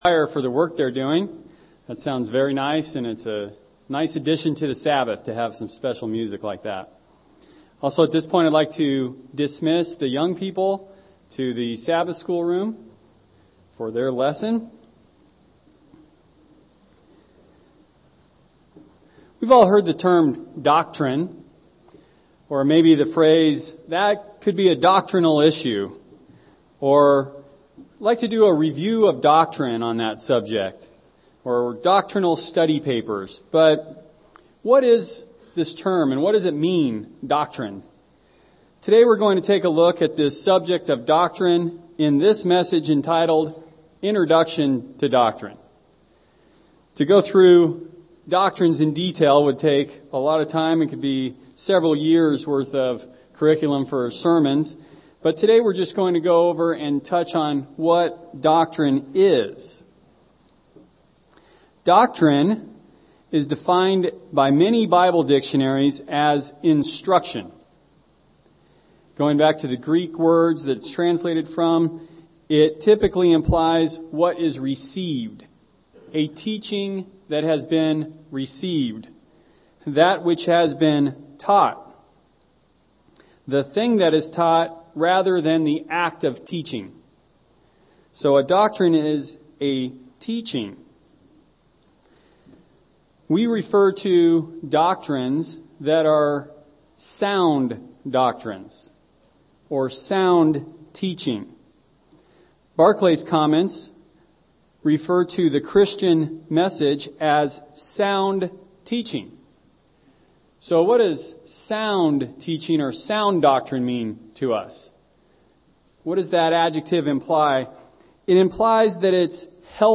UCG Sermon Notes Doctrine 1.